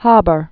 (häbər), Fritz 1868-1934.